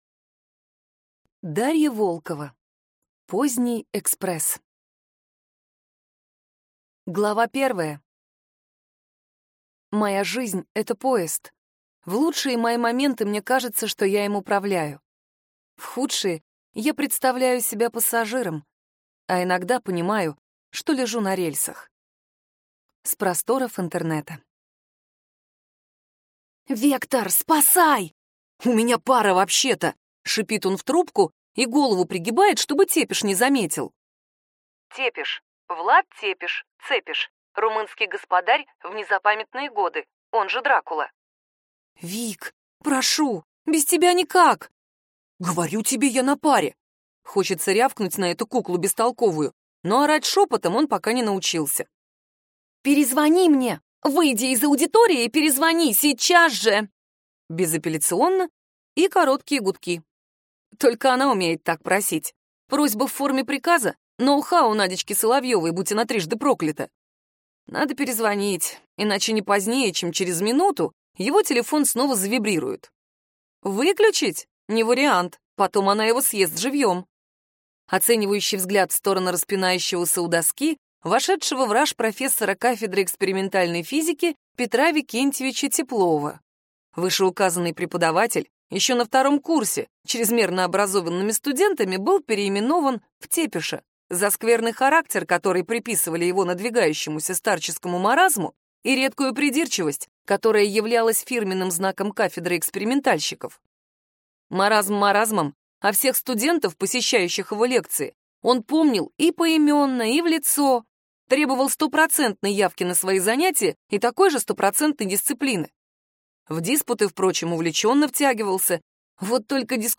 Аудиокнига Поздний экспресс | Библиотека аудиокниг